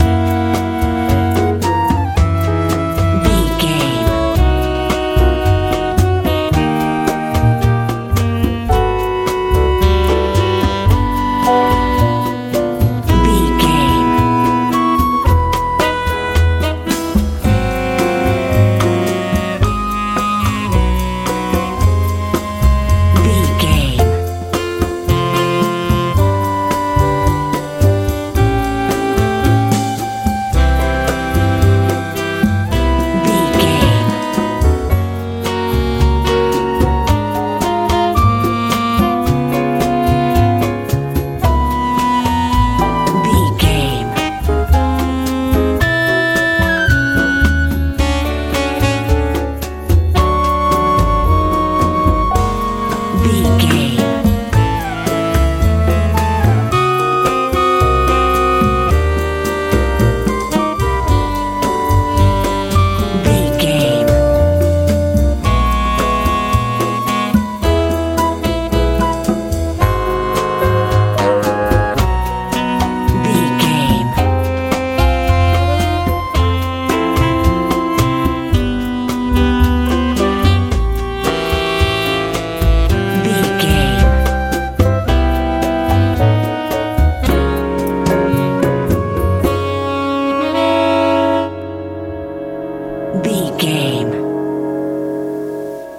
cuban latin music
Ionian/Major
D
light
smooth
piano
horns
bass guitar
drums
lively
driving